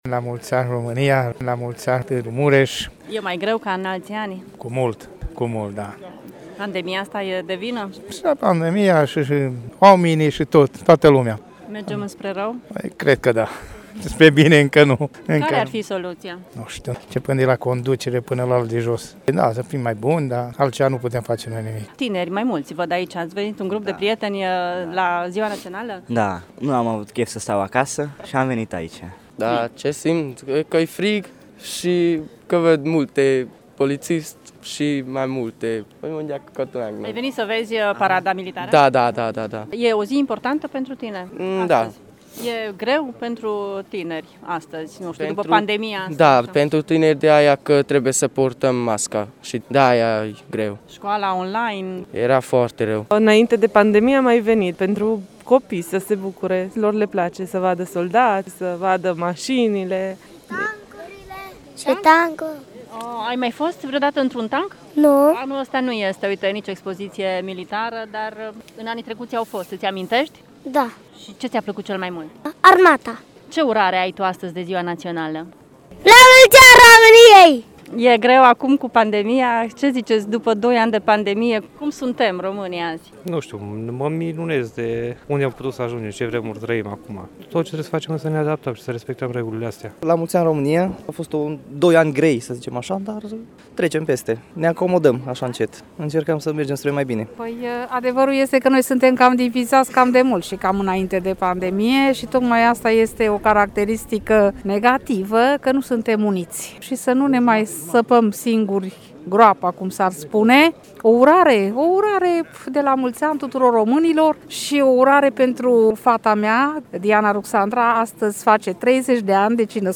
Târgumureșenii recunosc că românii s-au divizat mai mult acum, în pandemie, însă sunt optimiști deoarece ”speranța moare ultima” la români: